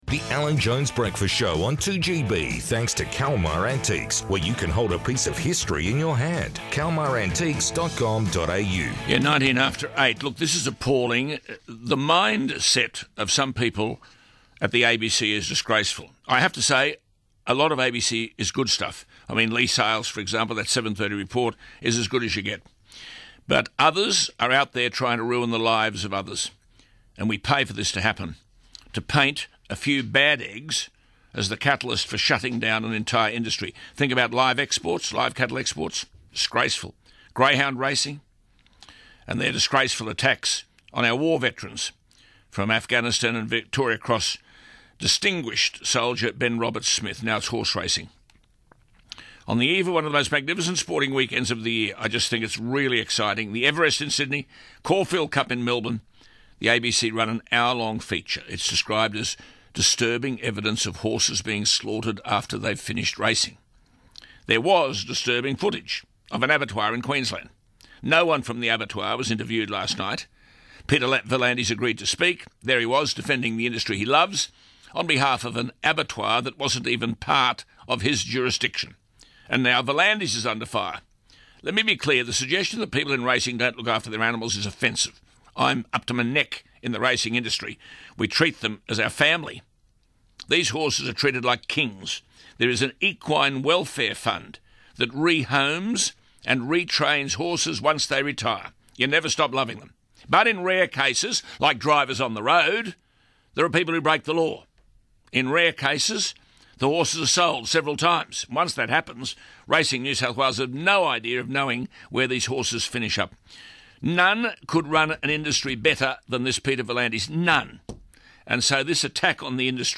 Racing NSW CEO Peter V'landys AM spoke to Alan Jones in response to the ABC's 7.30 Report from Thursday night.